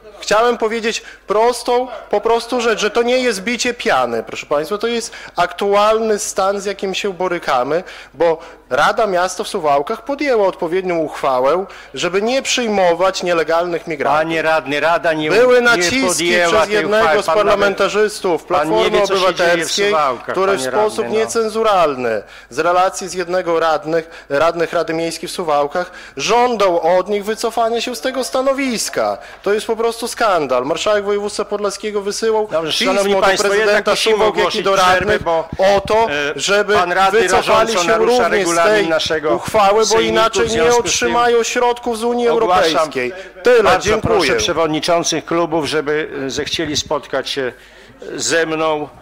Po kilku przerwach i kilkunastominutowej okupacji mównicy radnemu udało się wygłosić swoje zdanie.